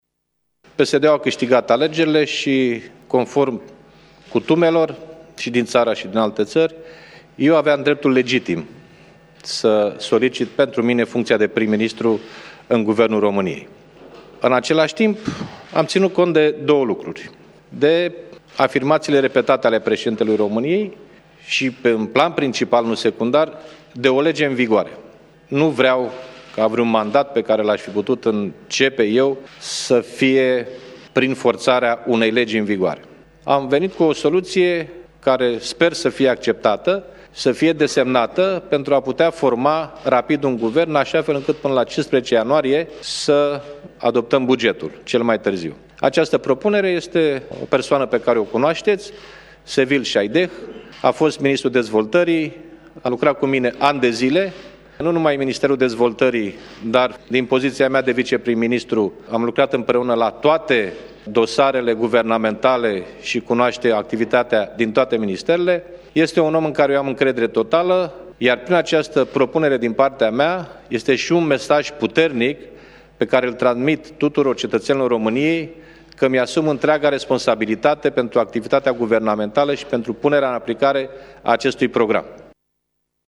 Anunțul a fost făcut de președintele PSD, Liviu Dragnea, la finalul consultărilor cu președintele Klaus Iohannis.